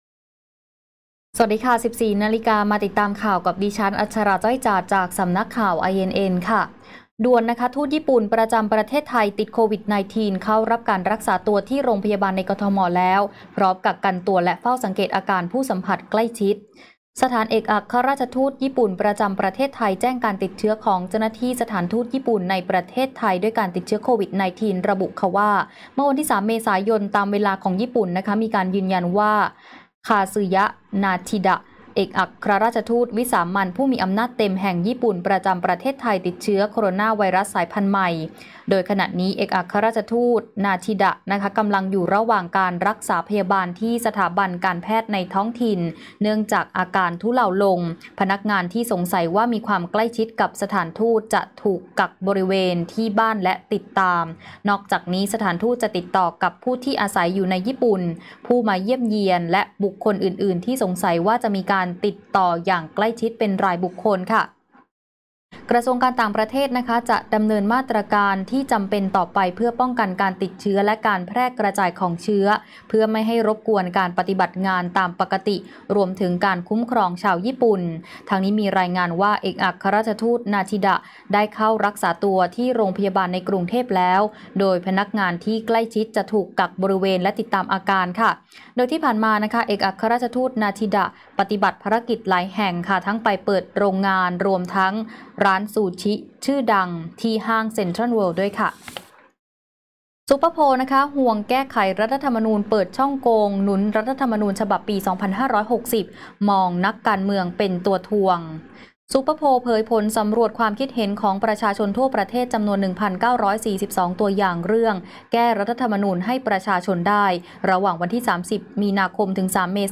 ข่าวต้นชั่วโมง 14.00 น.